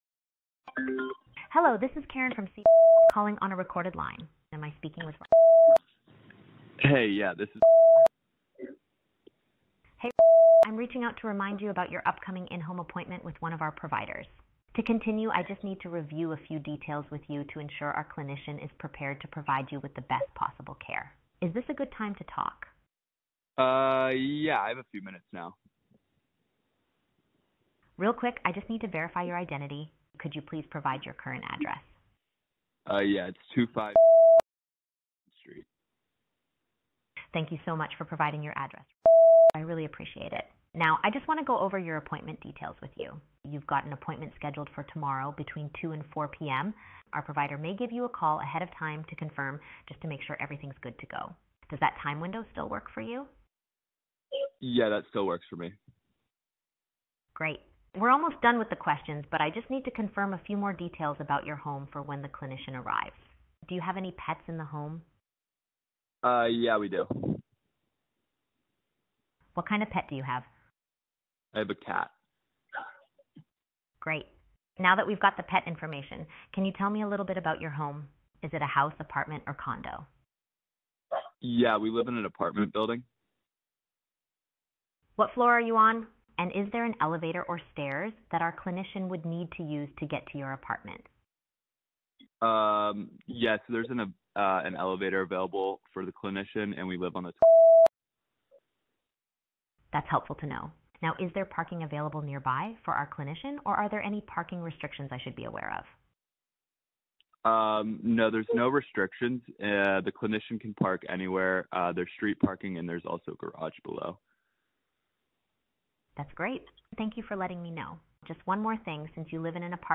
A Healthcare Phone Call handled by our AI VOICE AGENT
A-Healthcare-Phone-Call-handled-by-Our-AI-online-audio-converter.com_.wav